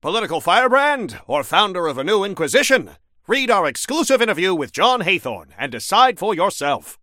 Newscaster_headline_36.mp3